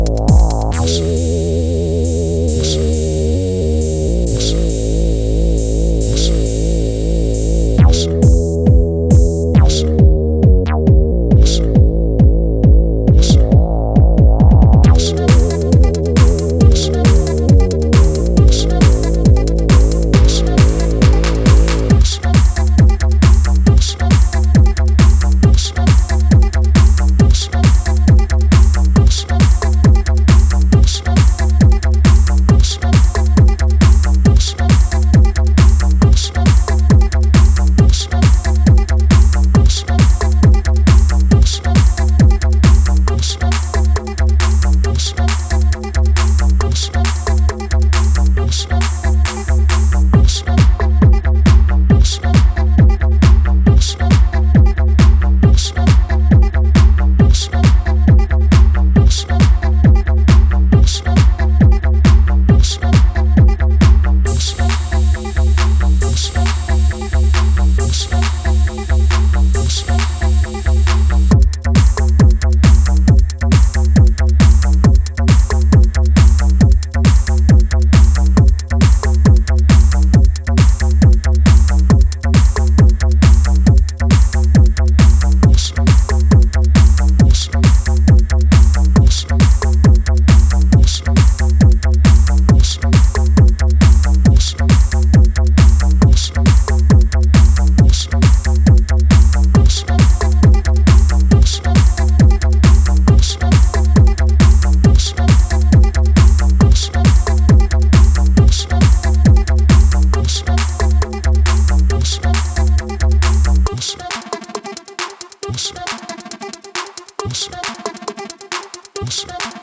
シカゴ・ハウス的暗黒アシッドリフがインパクト強いグルーヴァー
EBM感覚のダークネスが息づくテック・ハウスを展開